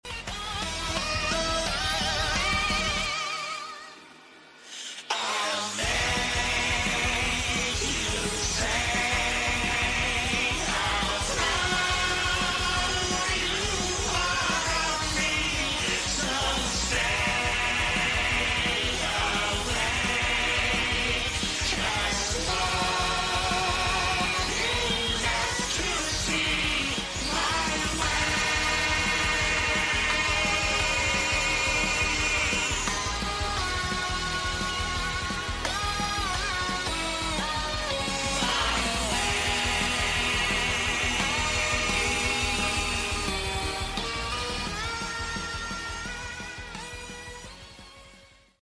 Female Cover